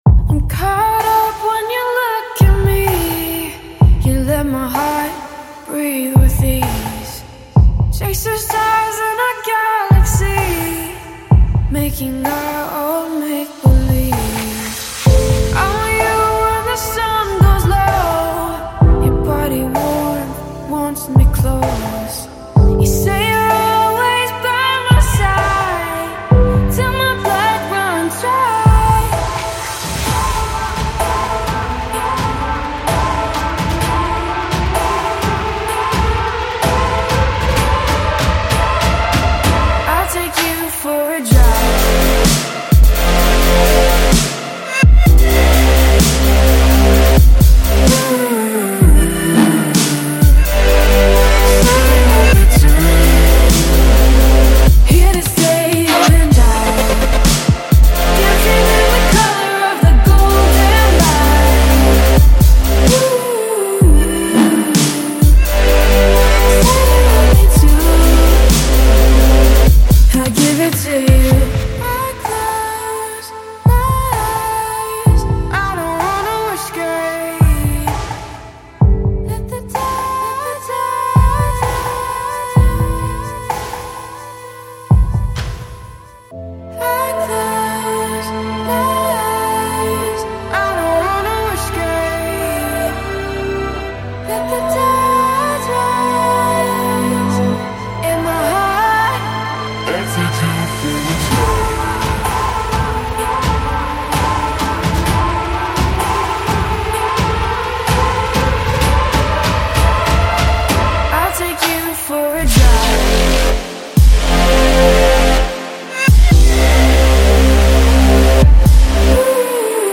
мягкие вокалы
глубокими басами и загадочными синтезаторами